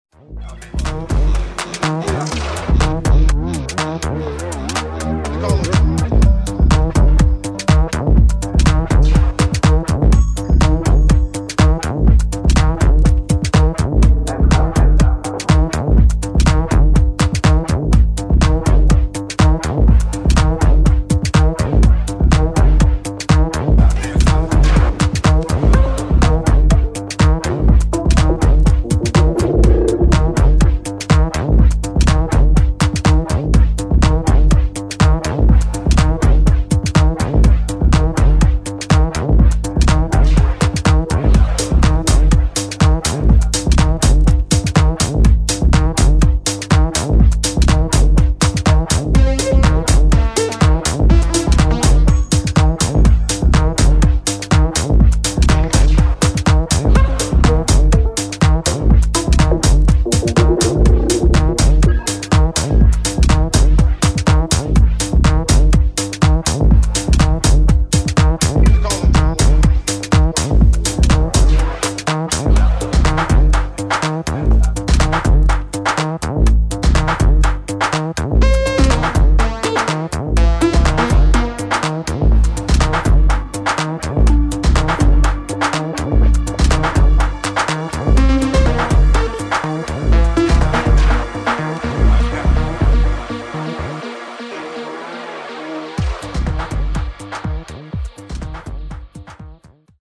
[ HOUSE | TECHNO | MINIMAL ]